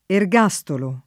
ergastolo [ er g#S tolo ] s. m.